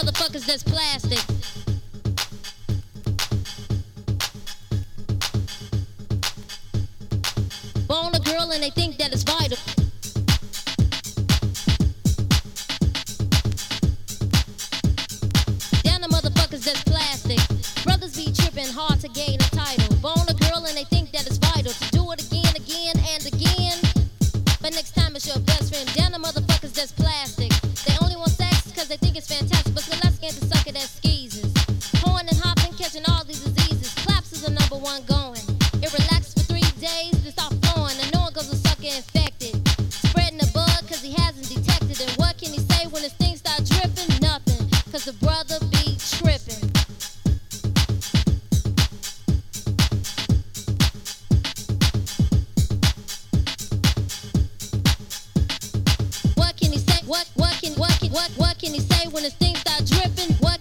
キッズ・ラッピンと、?き出しのドラムマシーントラックが最高！